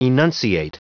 Prononciation du mot enunciate en anglais (fichier audio)
Prononciation du mot : enunciate